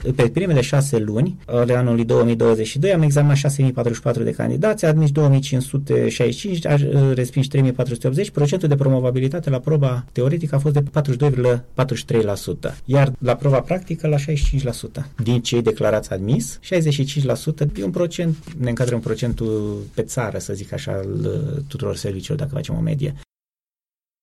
Despre procentul de promovabilitate din primele 6 luni a vorbit la Unirea FM comisarul șef Mihai Zgârdea, șeful Serviciului Public Comunitar Regim Permise de Conducere și Înmatriculare a Vehiculelor Alba